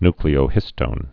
(nklē-ō-hĭstōn, ny-)